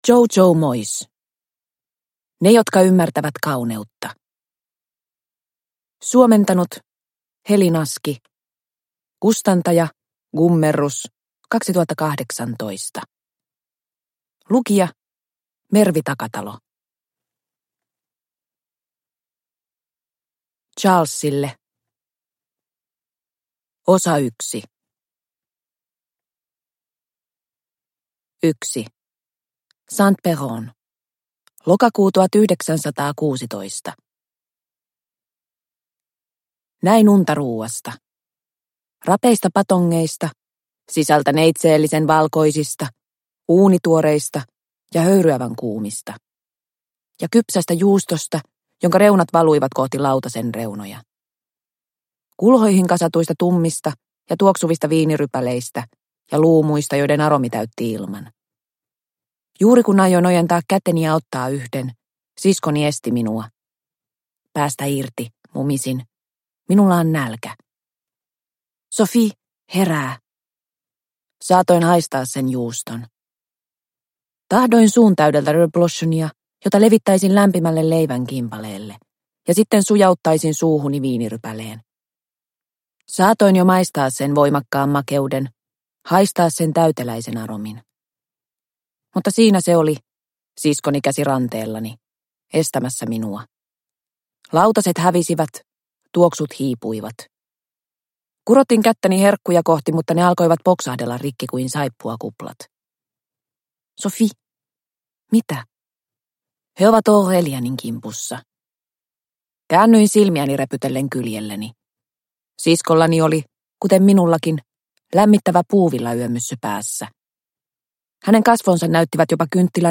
Ne jotka ymmärtävät kauneutta – Ljudbok – Laddas ner